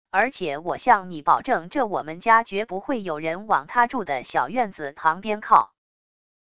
而且我向你保证这我们家决不会有人往他住的小院子旁边靠wav音频